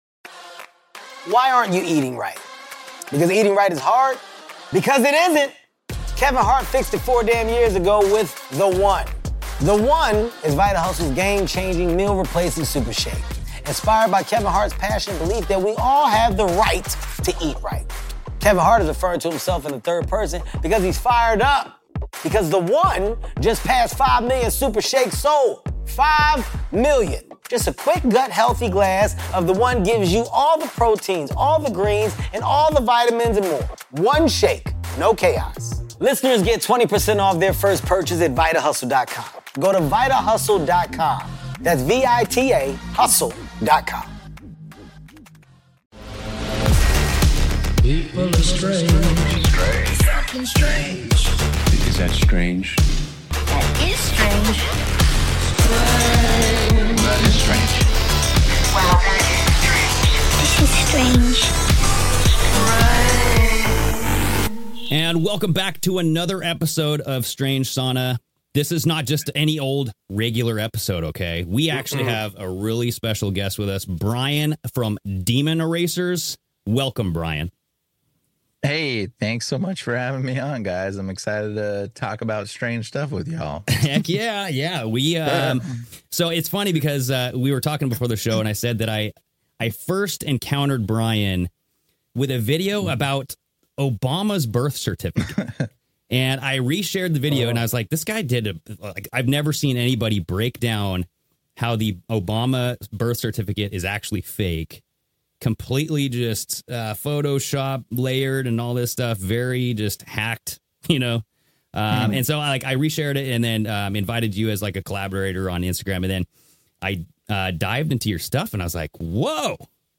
Note: Apologies for the poor audio between 27:47 - 41:41.